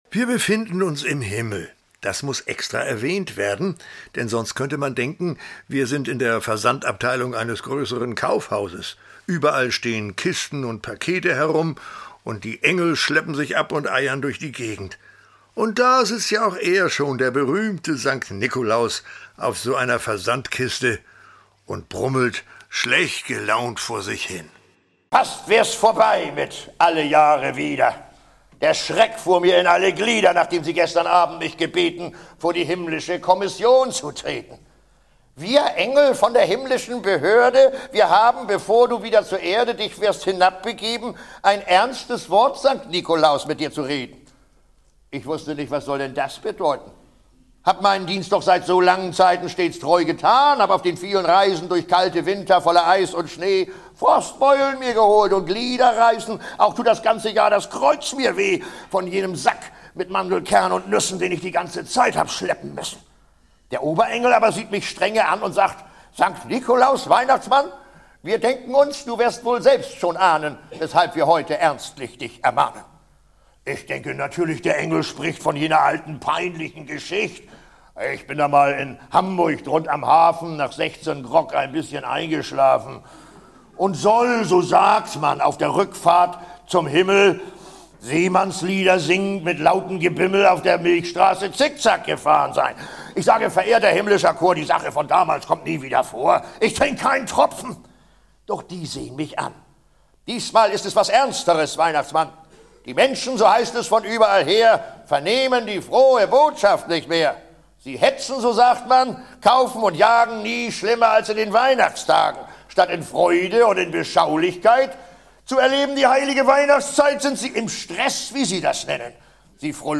Wer nimmt Oma? - Hans Scheibner - Hörbuch